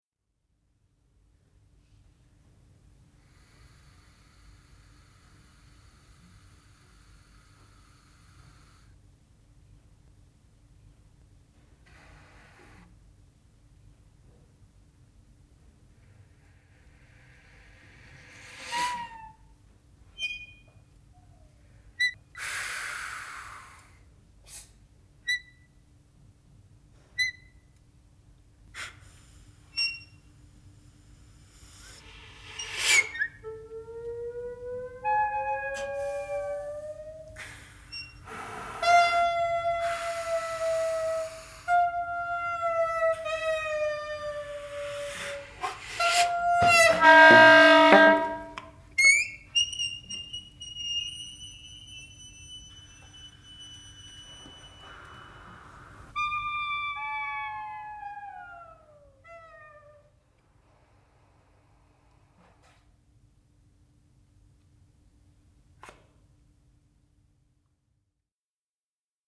for saxophone ensemble